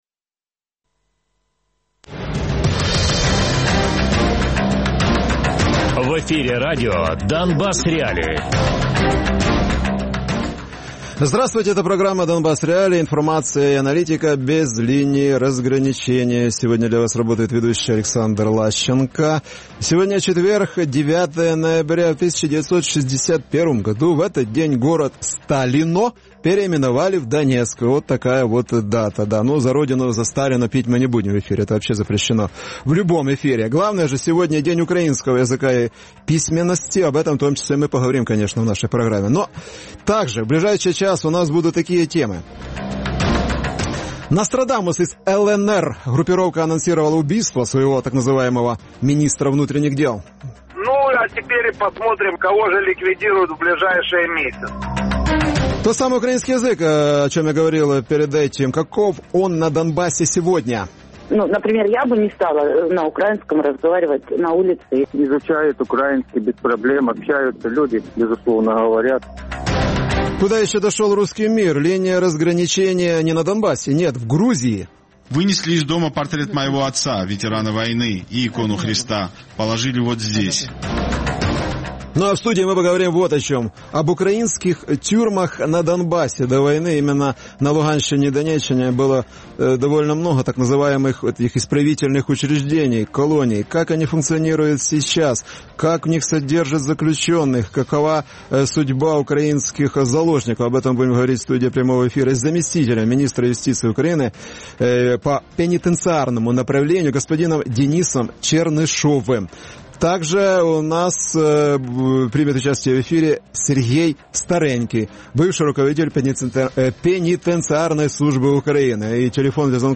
Гості: Денис Чернишов - заступник Міністра юстиції України (пенітенціарний напрямок), Сергій Старенький - екс-глава ДПтСУ Радіопрограма «Донбас.Реалії» - у будні з 17:00 до 18:00. Без агресії і перебільшення. 60 хвилин про найважливіше для Донецької і Луганської областей.